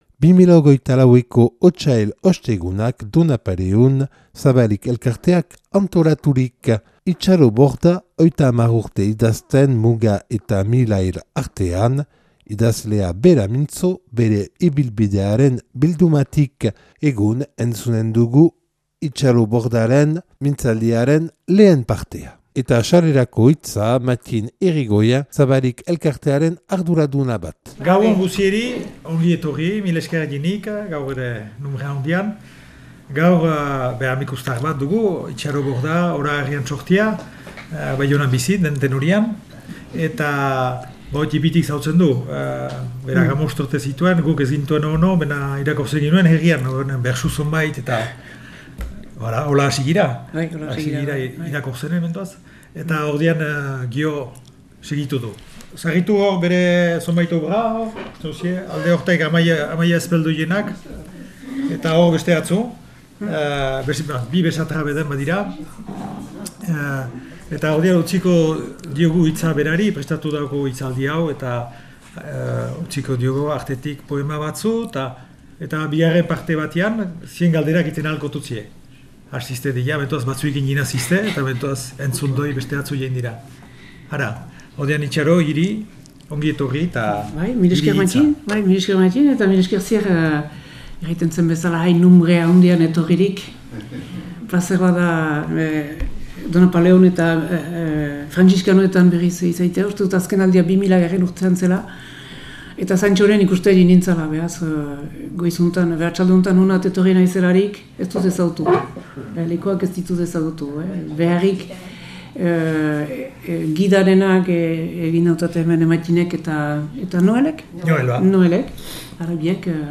Idazlea bera mintzo, bere ibilbidearen bildumatik.
Otsail Ostegunak 2024 Donapaleun Otsailaren 15ean Zabalik elkarteak antolaturik.